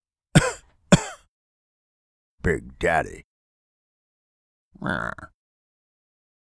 COUGH BIG DADDY
big cough coughing daddy funny humor sound effect free sound royalty free Funny